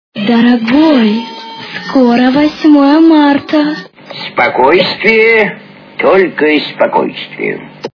» Звуки » Смешные » Дорогой, скоро 8 Марта! - Спокойствие, только спокойствие!
При прослушивании Дорогой, скоро 8 Марта! - Спокойствие, только спокойствие! качество понижено и присутствуют гудки.